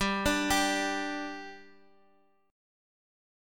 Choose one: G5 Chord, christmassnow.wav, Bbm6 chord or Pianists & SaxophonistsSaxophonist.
G5 Chord